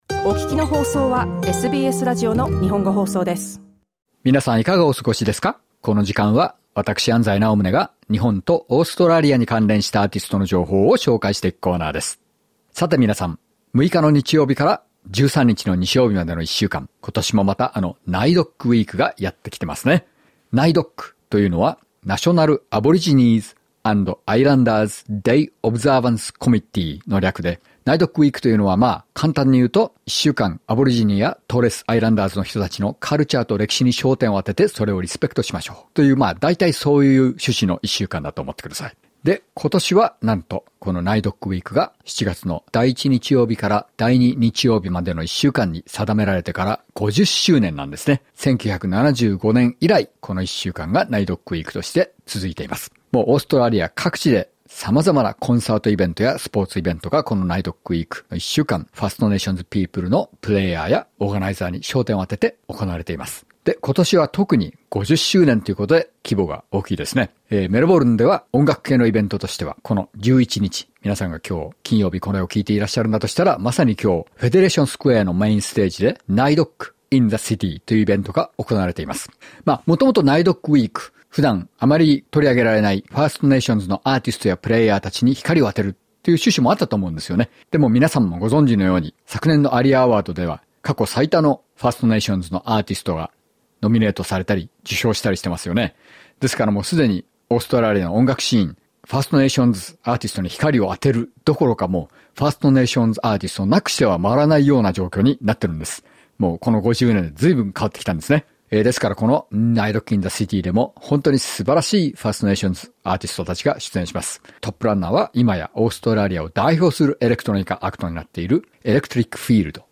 「リップル・エフェクト・バンド」は、トップエンド地域の「ソルトウォーター・ロック」の伝統を受け継ぎながらも、このコミュニティから生まれた初の、女性だけで構成され、自ら楽器を演奏するバンドとして、新たな道を切り開いています。 自分たちの言語であるンジェッバナ語、ブララ語、ナカラ語、クネ語で歌い、土地、言葉、文化にまつわる物語を伝えています。